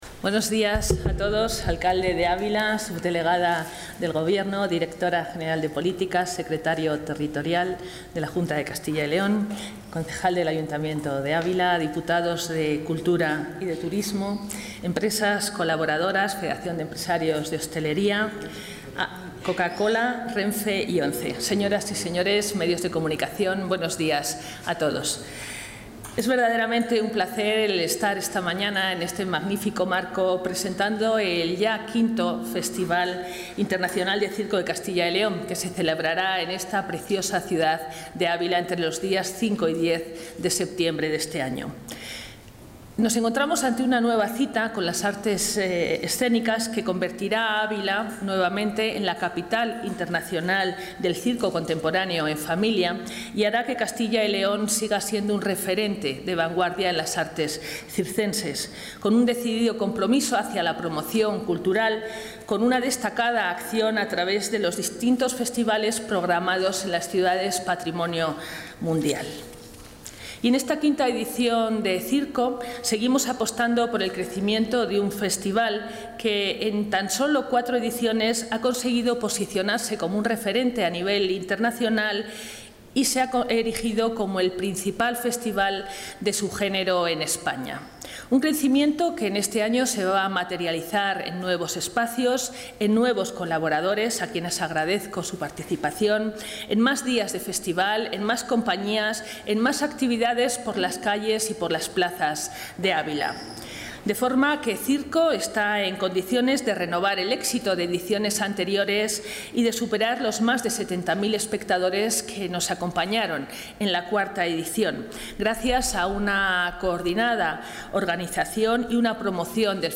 Intervención de la consejera de Cultura y Turismo.